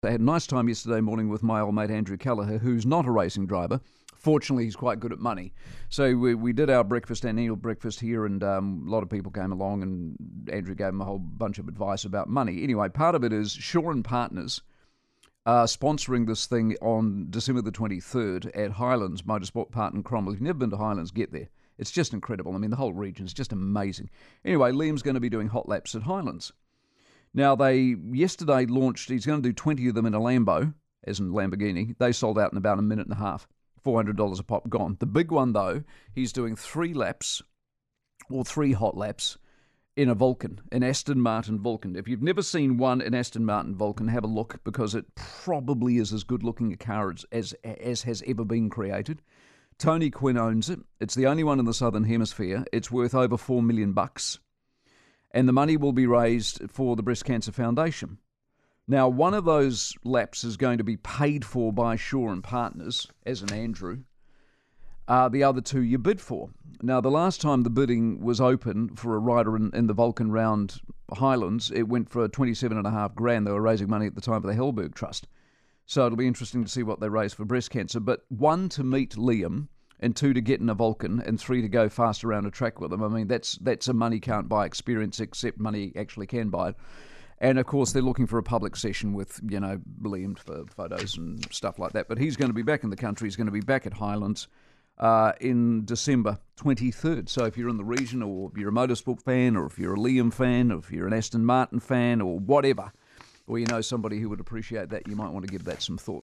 We're excited to share that our competition was recently mentioned on Newstalk ZB. Hear what Mike Hosking had to say below: